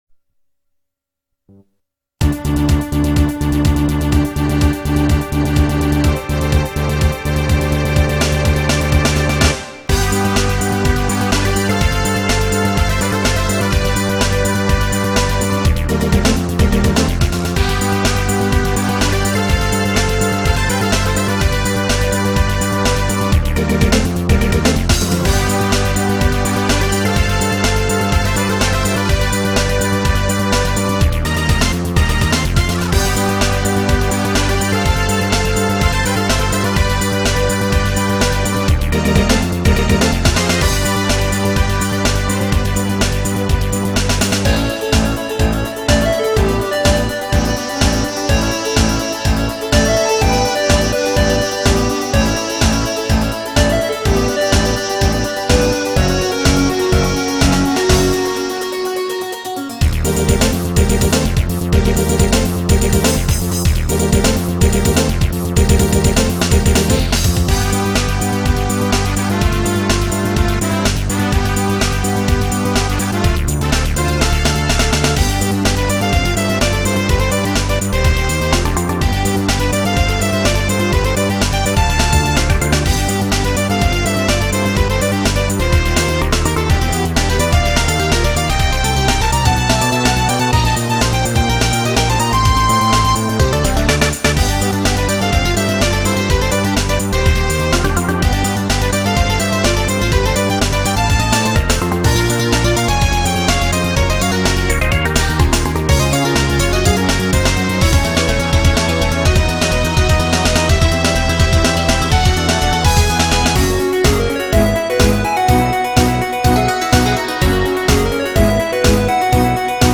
BPM62-125
Audio QualityCut From Video